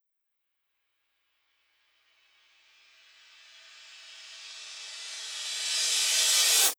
Tm8_HatxPerc11.wav